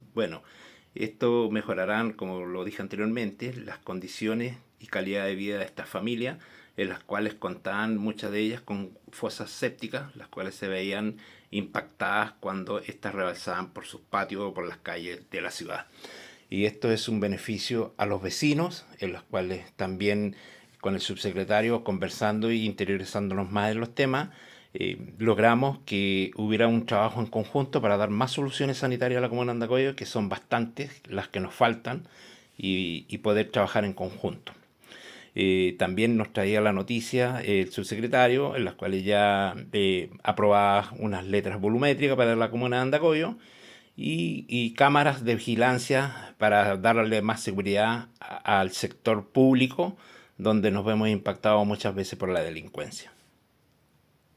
El concejal de la comuna Javier Cifuentes valoró que las obras
Concejal-Javier-Cifuentes.mp3